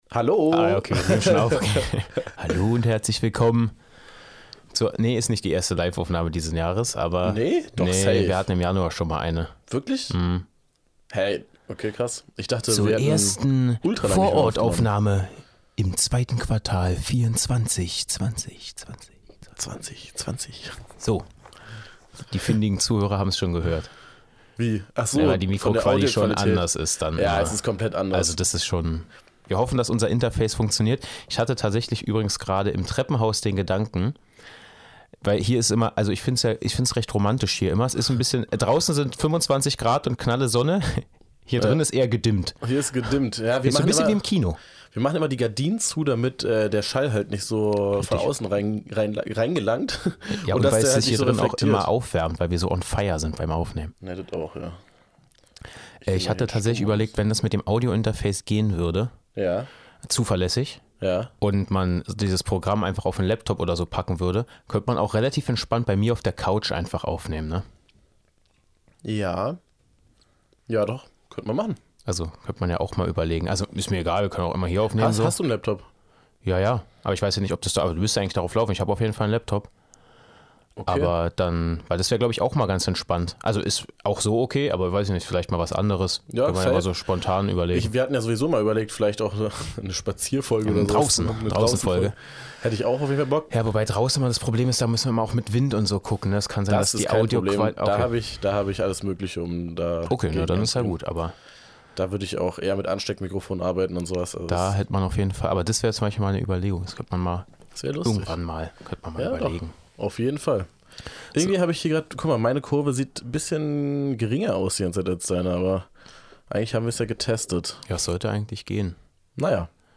Seit langem mal wieder eine hitzige Diskussionsfolge mit vor-Ort-Aufnahme